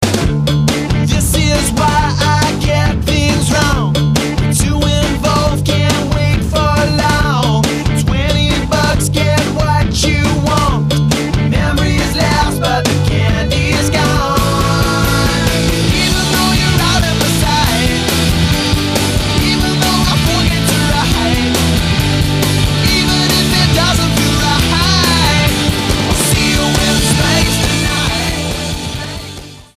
STYLE: Rock
deliciously catchy